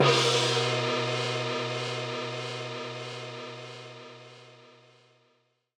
Gong-Long.wav